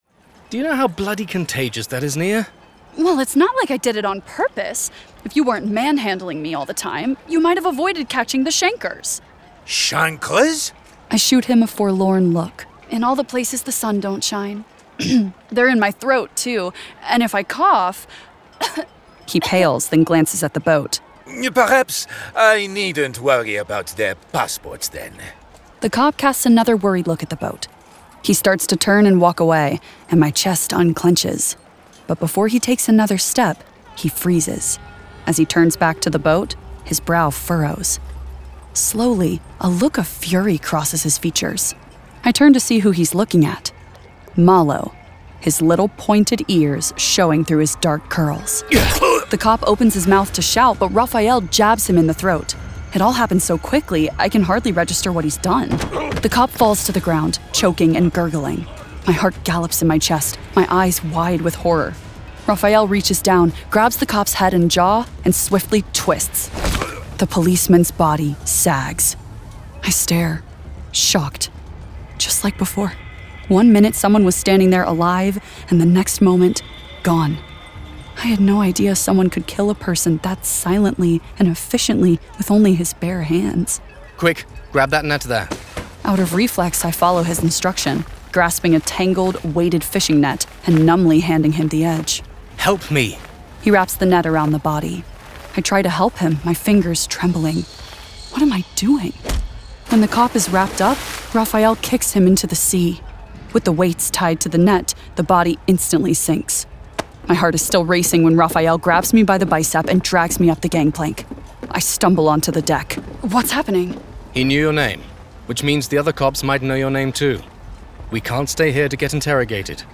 Full Cast. Cinematic Music. Sound Effects.
Genre: Fantasy Romance
Adapted from the novel and produced with a full cast of actors, immersive sound effects and cinematic music!